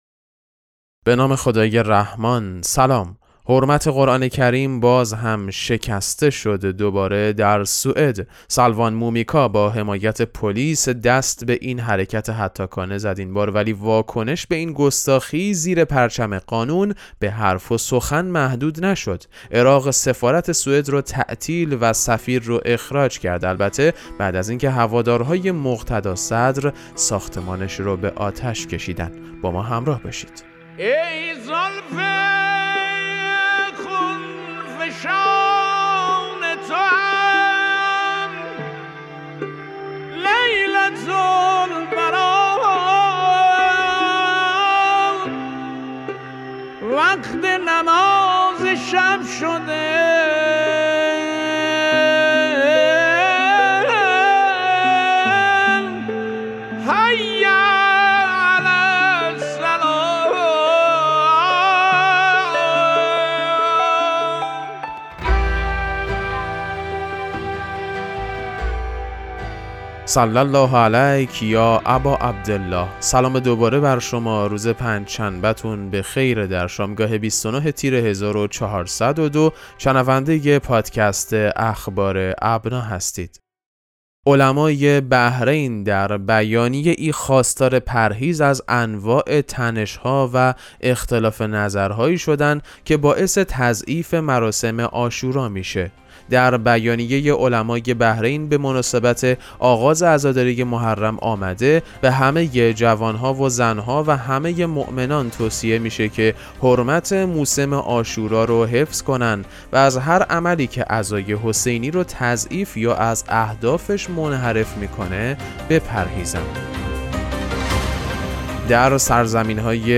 پادکست مهم‌ترین اخبار ابنا فارسی ــ 29 تیر 1402